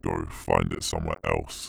Voice Lines / Marcel drug fiend
Update Voice Overs for Amplification & Normalisation